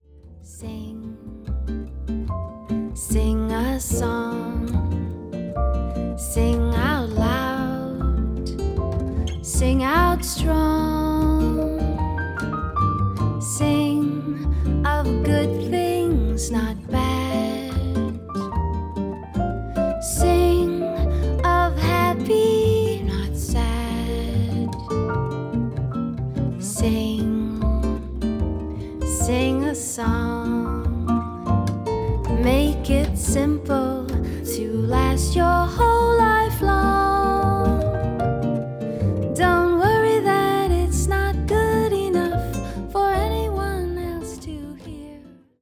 カナダ人ジャズ・シンガー